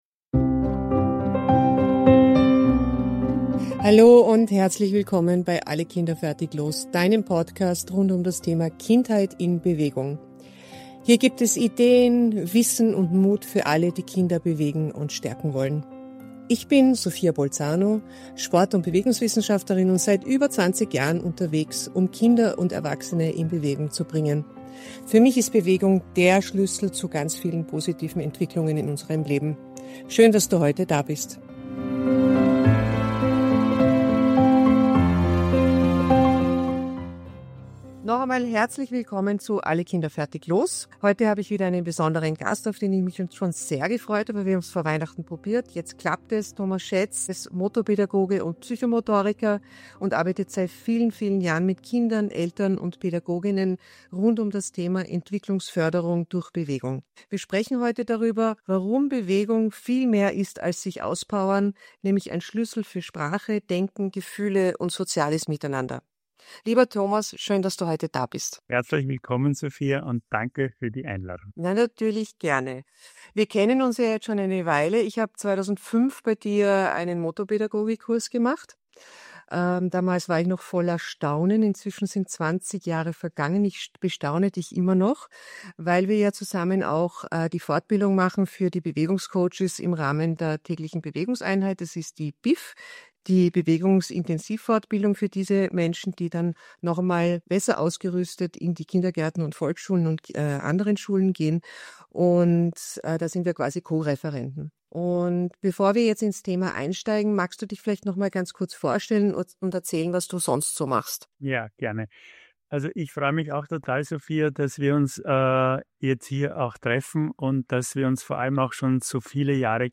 Es geht um sichere Beziehungen, um mutige Kinder, um Räume zum Ausprobieren und darum, wie Bewegung kognitive, emotionale und soziale Prozesse anstößt. Ein Gespräch, das Eltern, Pädagog:innen und alle, die mit Kindern leben oder arbeiten, zum Nachdenken bringt – und Mut macht, Bewegung als Entwicklungschance zu sehen.